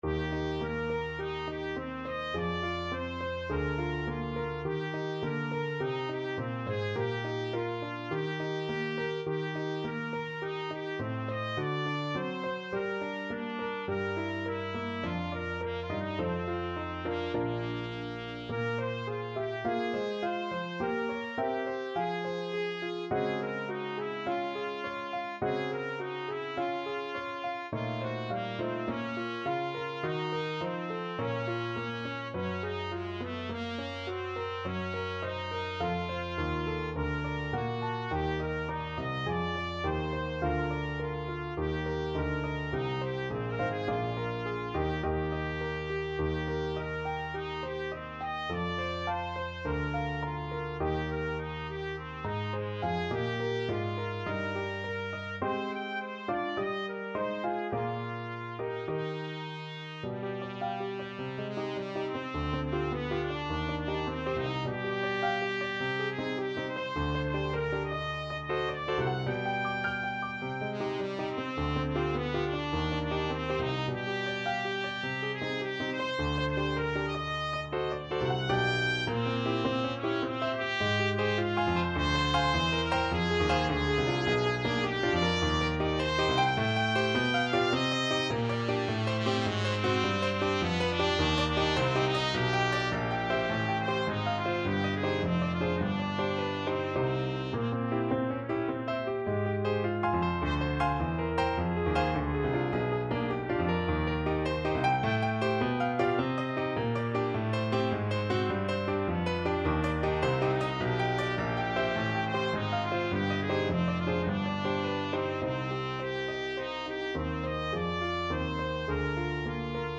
4/4 (View more 4/4 Music)
Einfach, innig =104
Classical (View more Classical Trumpet Music)